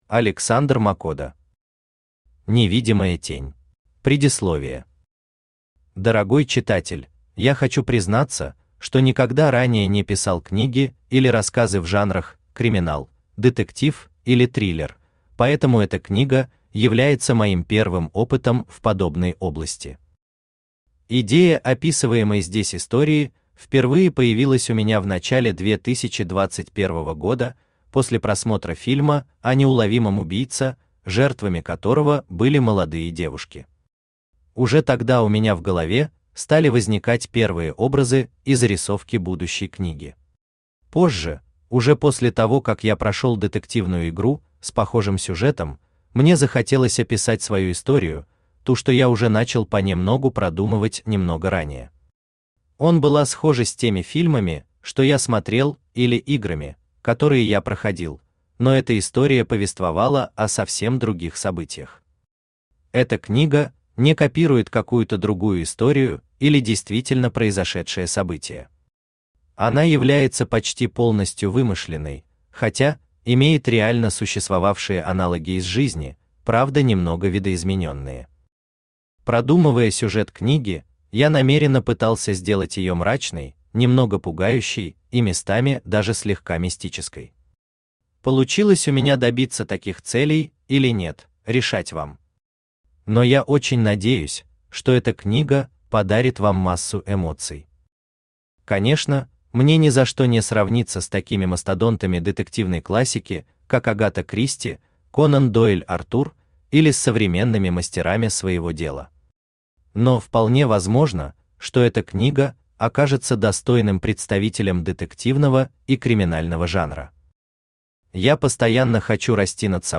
Аудиокнига Невидимая Тень | Библиотека аудиокниг
Aудиокнига Невидимая Тень Автор Александр Макода Читает аудиокнигу Авточтец ЛитРес.